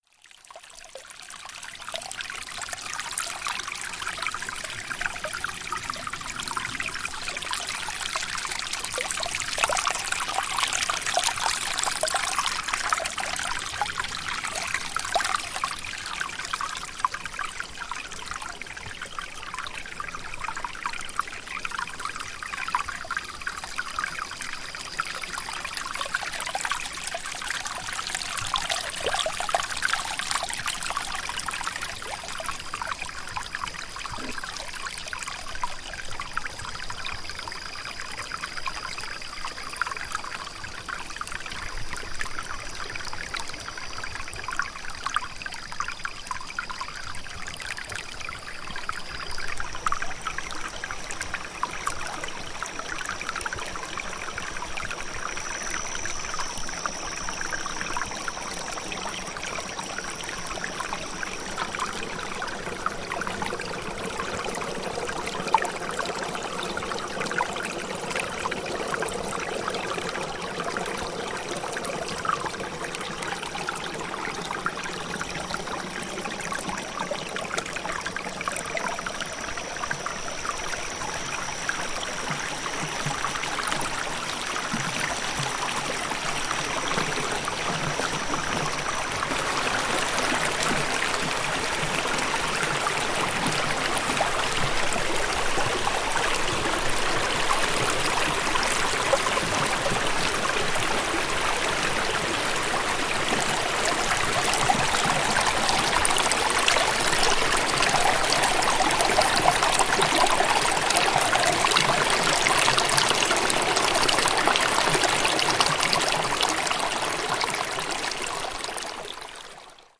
Extrait 2 : Ruisseau, Vent, Feu – 2
1_Ruisseau_Vent_Feu_2.mp3